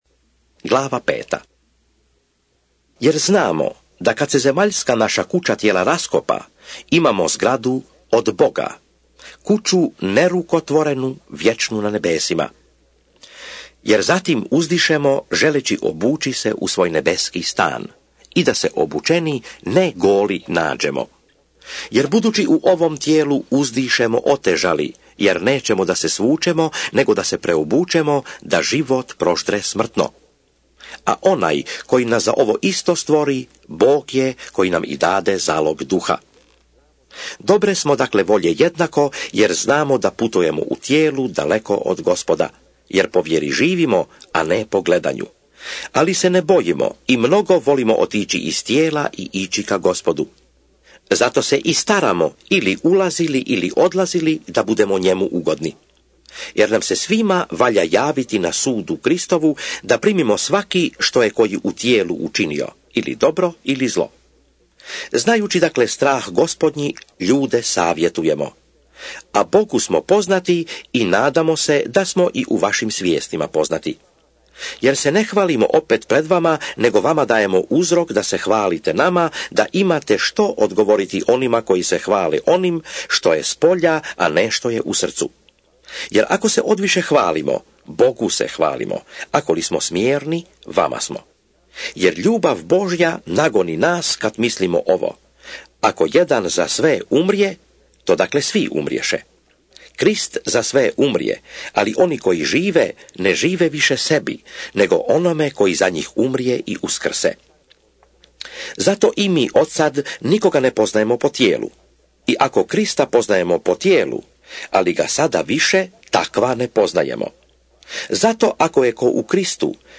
2 KORINĆANI(ČITANJE) - Bible expounded